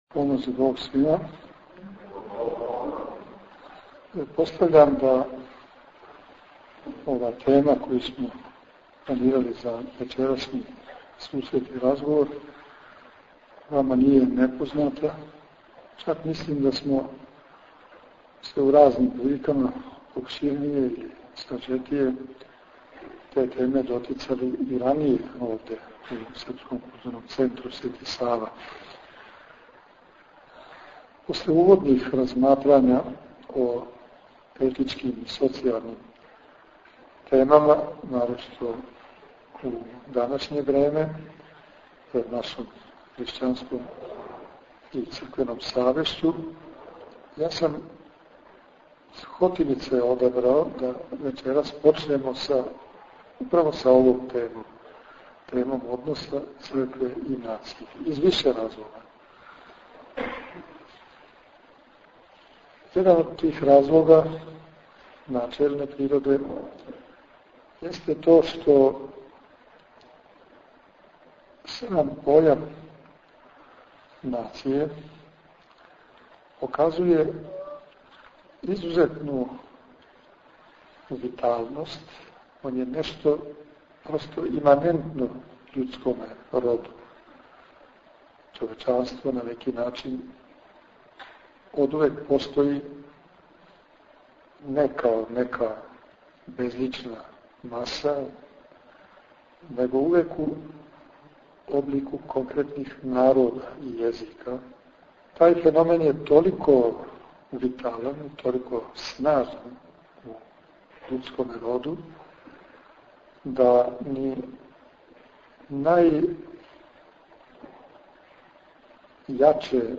У оквиру циклуса предавања на тему Социолошки и етички изазови пред хришћанском свешћу данас, Његово Преосвештенство Епископ бачки Господин Др. Иринеј, у четвртак 24. новембра 2011. године, беседио је пред слушаоцима сабраним у свечаној дворани Српског културног центра Свети Сава у Суботици, на тему Црква и нација.